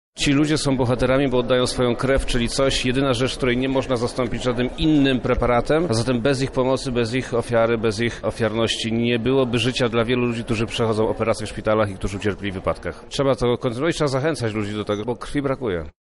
Jak mówi Przemysław Czarnek, wojewoda lubelski ten dar jest nie do przecenienia: